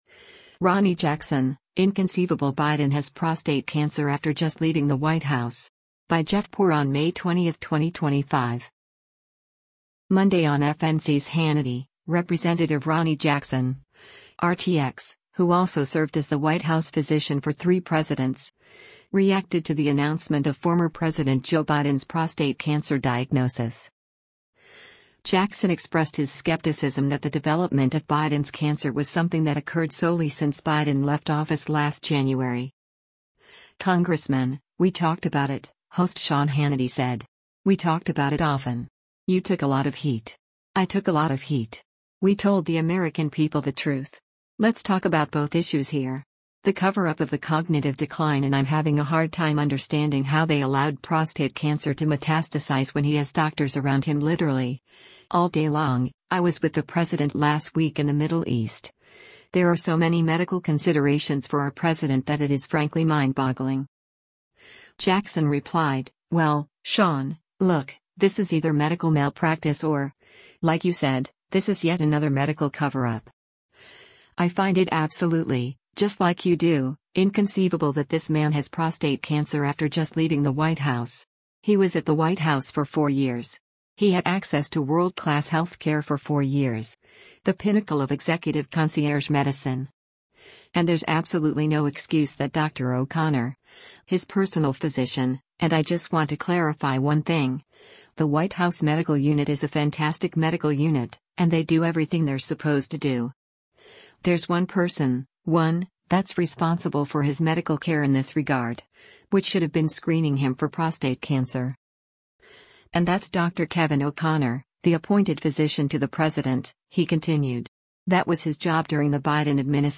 Monday on FNC’s “Hannity,” Rep. Ronny Jackson (R-TX), who also served as the White House physician for three presidents, reacted to the announcement of former President Joe Biden’s prostate cancer diagnosis.